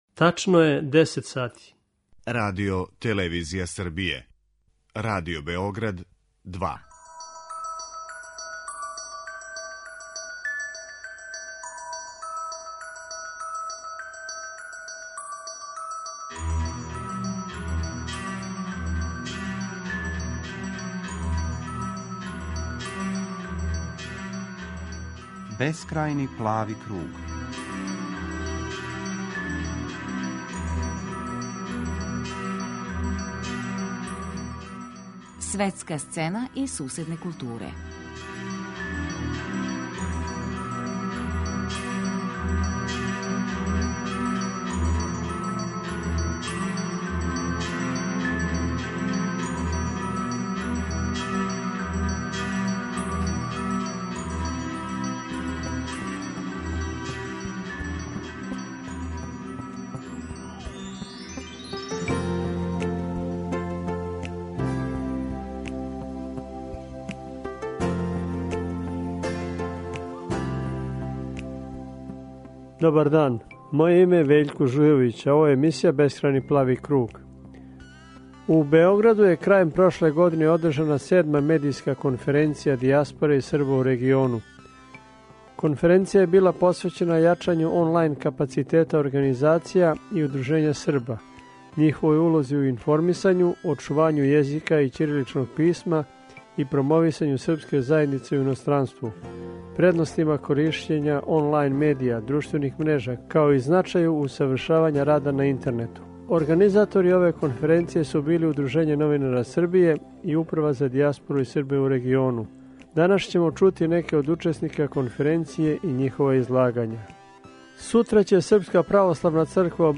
Данас ћемо чути неке од учесника конференције и њихова излагања.